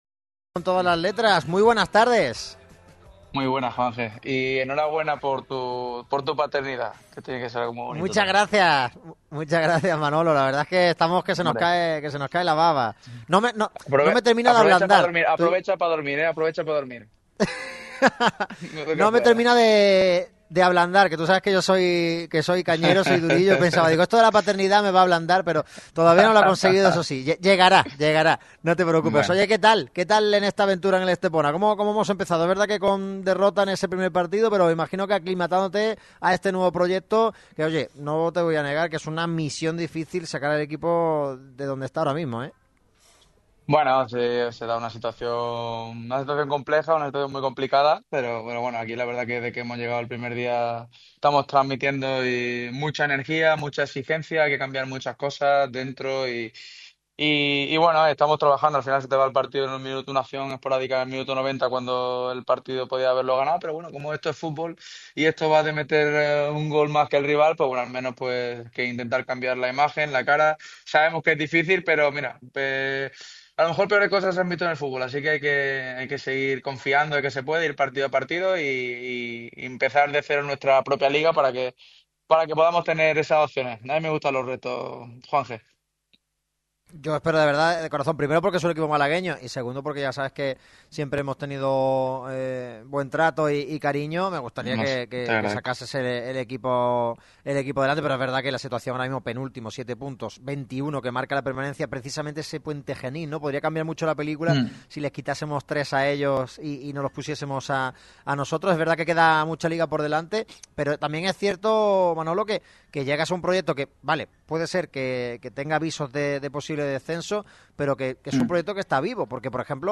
Entrevista con sabor a Tarragona en Radio MARCA Málaga.